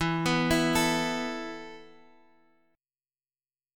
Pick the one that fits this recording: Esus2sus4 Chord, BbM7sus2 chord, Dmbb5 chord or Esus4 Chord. Esus4 Chord